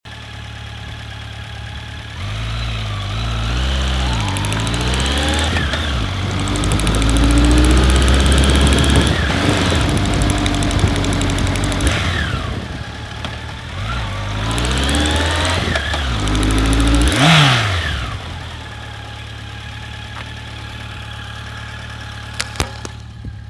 Klickern auf der rechten Seite am Zylinderkopf - Honda CB 1100 Forum
Hallo, habe seit einiger Zeit beim Beschleunigen auf der rechten Seite ein Geräusch als wenn ein Ventil klappert.